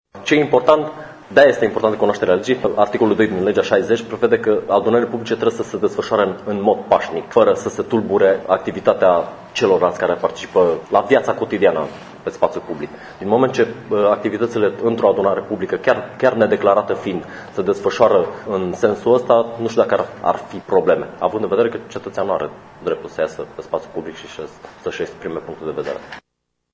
Șeful Inspectoratului de Jandarmi al Județului Mureș, Tiberiu Adrian Filipaș, a spus astăzi că cetățenii au dreptul de a se manifesta atâta timp cât sunt pașnici și nu aduc atingere normelor privind ordinea publică: